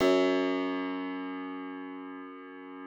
53l-pno04-F0.aif